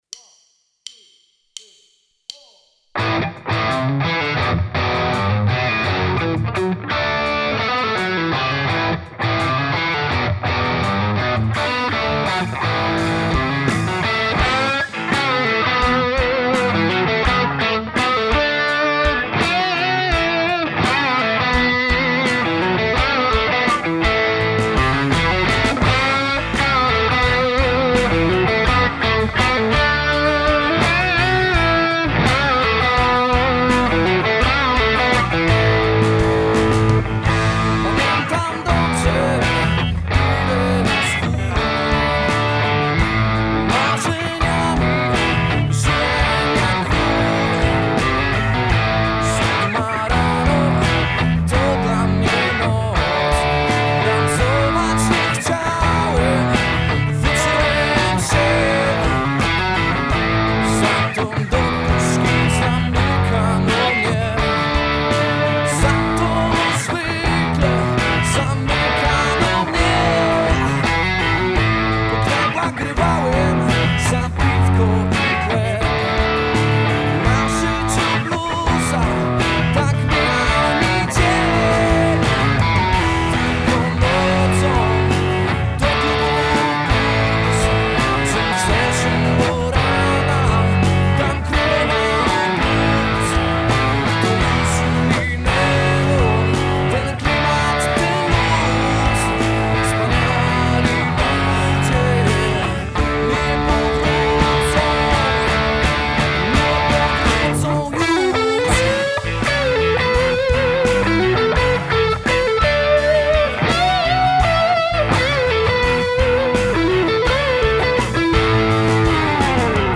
Pobierz ścieżkę gitary elektrycznej (.mp3)